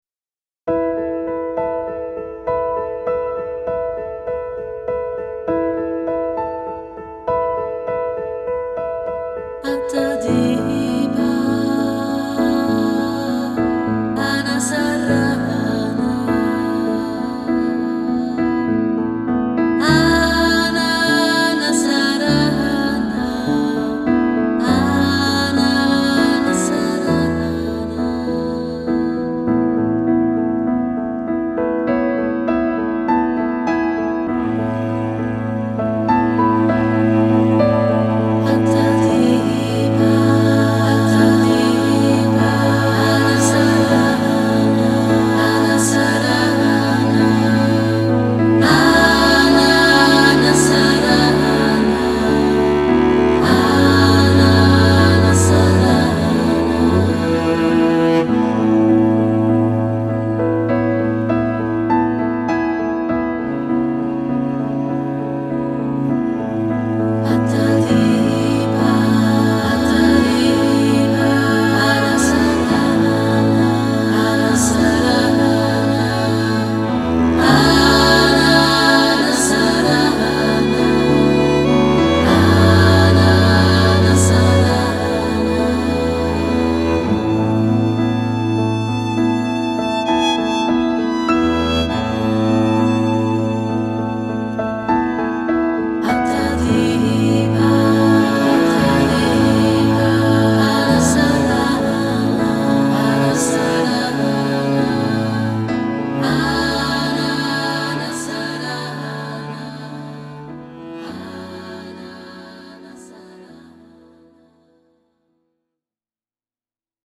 Een zeer meditatieve mantracd die diep heelt.